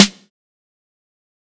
TC SNARE 19.wav